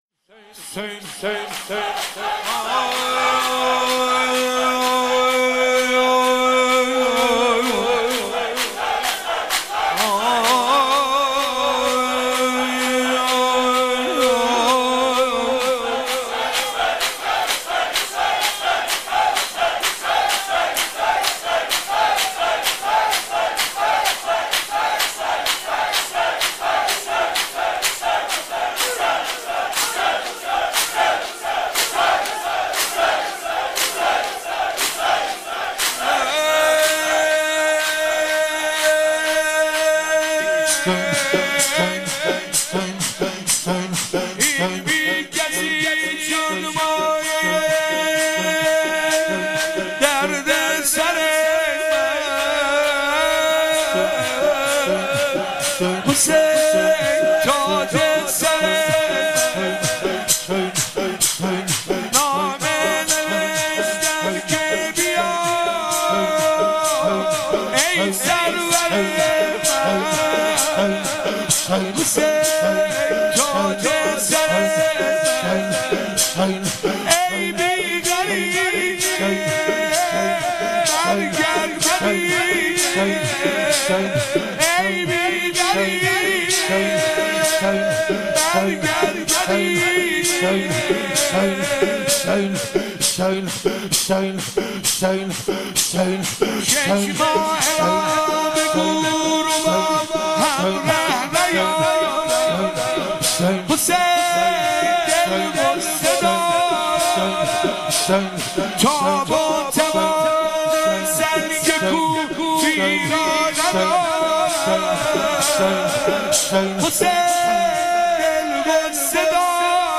شور پایانی